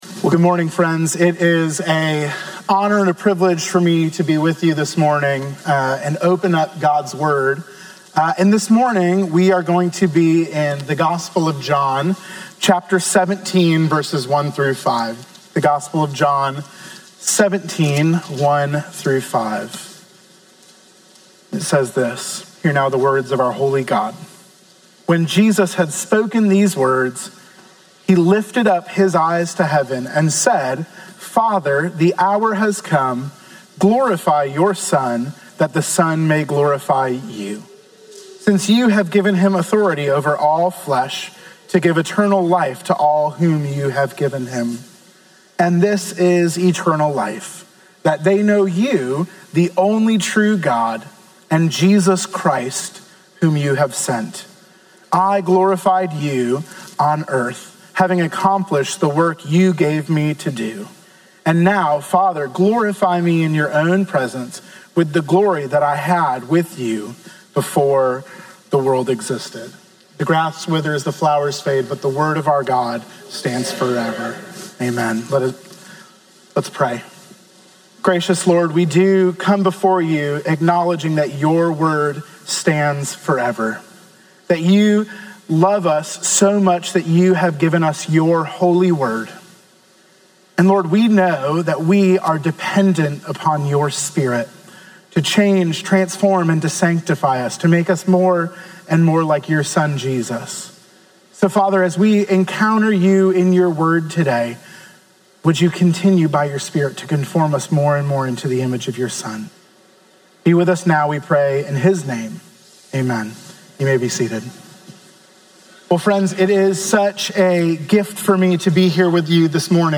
Sermon on John 17:1-5 from July 20